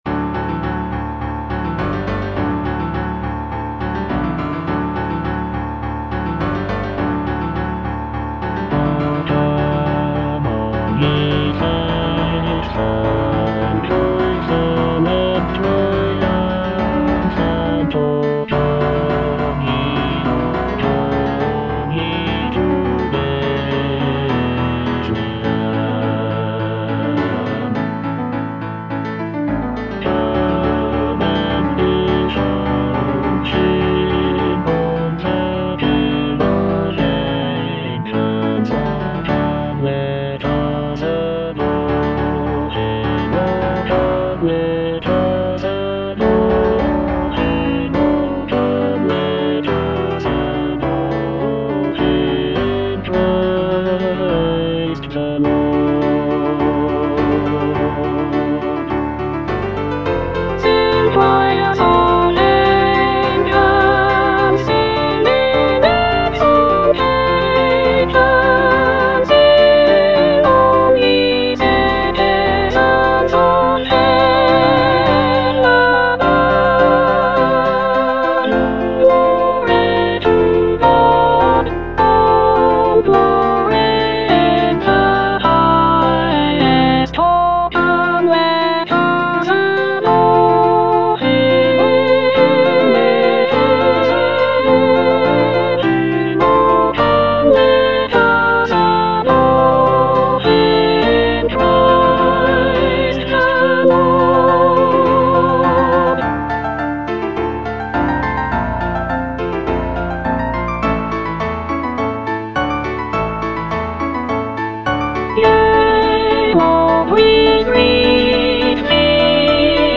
Soprano II (Emphasised voice and other voices)
Christmas carol
incorporating lush harmonies and intricate vocal lines.